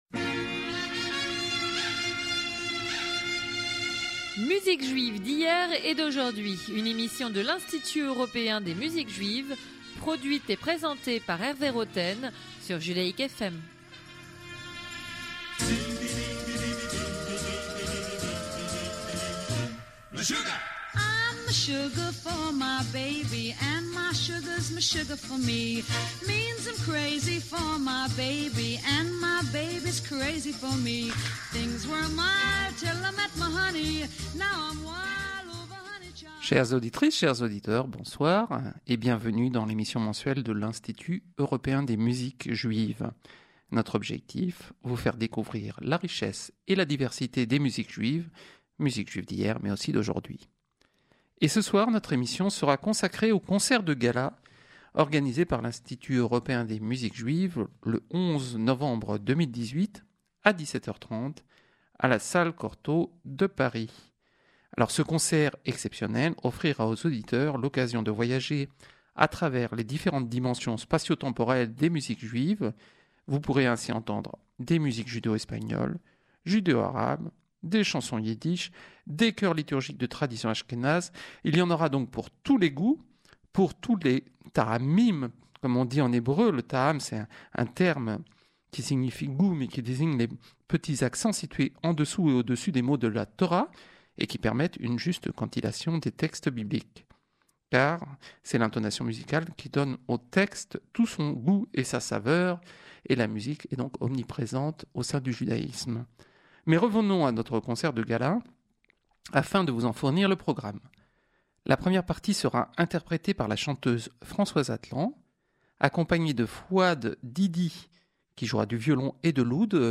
Radio program in French